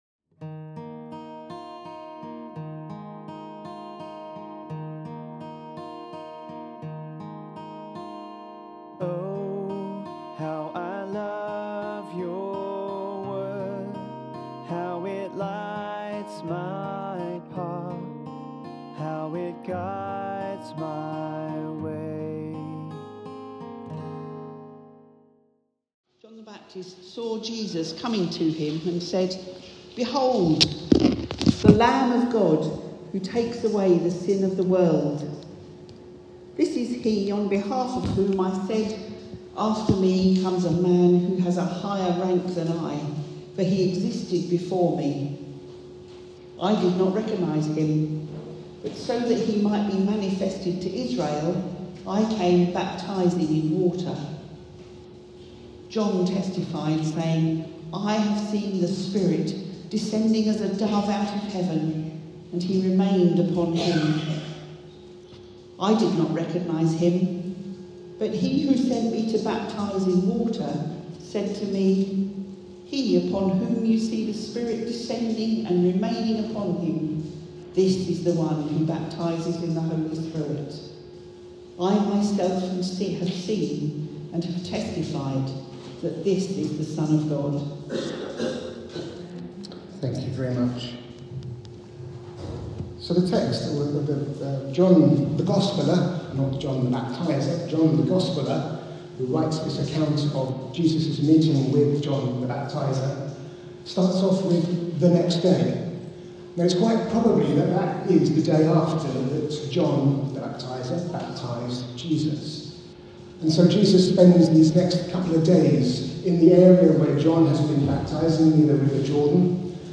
Today we consider 'Jesus, Lamb of God' from John 1:29-34, Genesis 22:3-14 and Revelation 7:9-17. (With many apologies for the poor quality of the recording and missing the first two minutes)